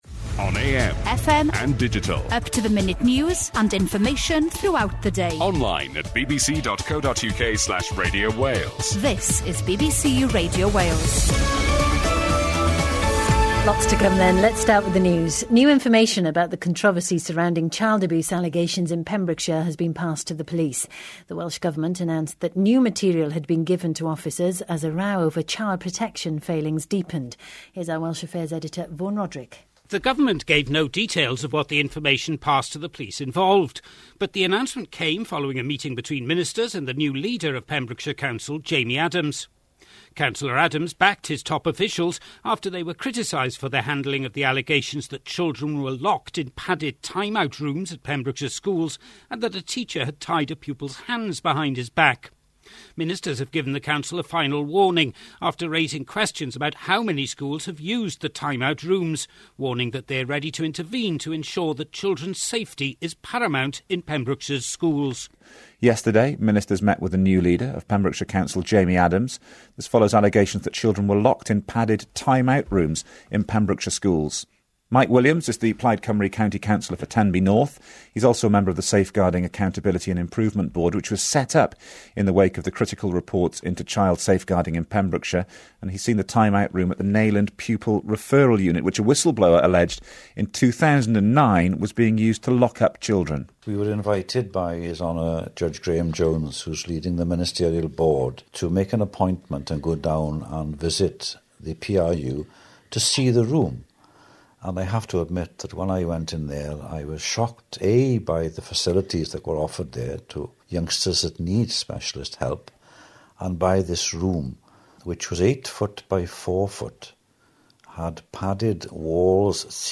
The leader of Pembrokeshire County Council Jamie Adams gave a live telephone interview to BBC Radio Wales yesterday morning.